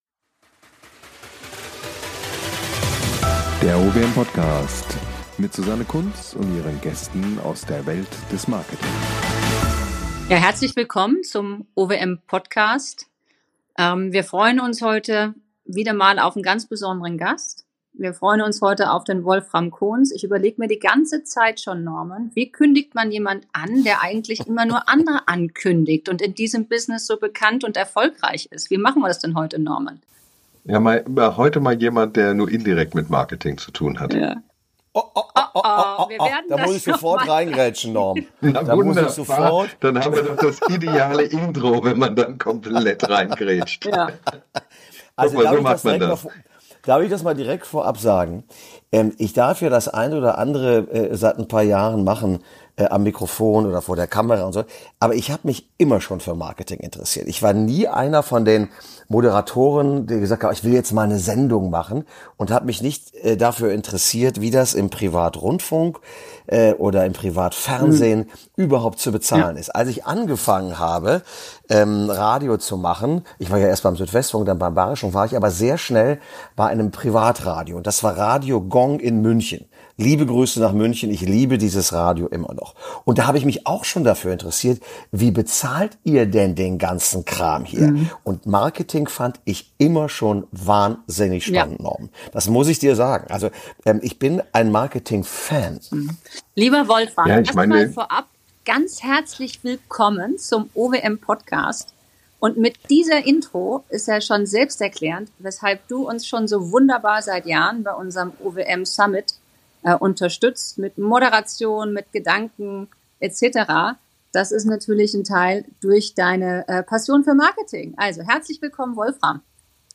#12 Wolfram Kons, Journalist und Fernsehmoderator, Im Gespräch mit der OWM ~ Der OWM Podcast
Wolfram Kons, bekannter Journalist und Fernsehmoderator ist heute zu Gast bei der OWM. Er spricht mit uns über aktuelle Fragen und Herausforderungen in der Medienbranche u.a. welche Folgen Werbeverbote haben können.